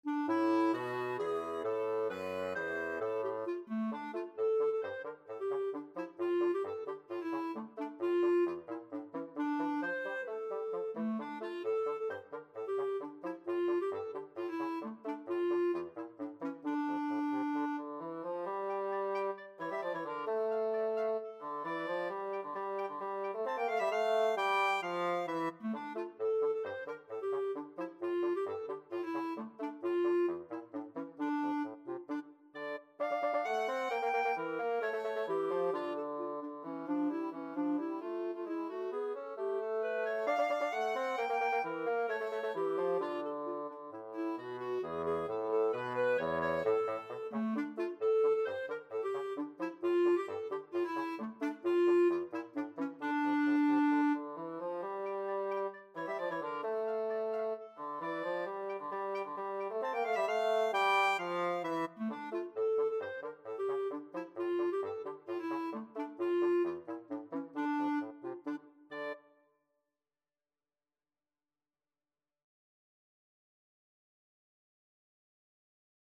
Free Sheet music for Clarinet-Bassoon Duet
ClarinetBassoon
D minor (Sounding Pitch) (View more D minor Music for Clarinet-Bassoon Duet )
2/4 (View more 2/4 Music)
Traditional (View more Traditional Clarinet-Bassoon Duet Music)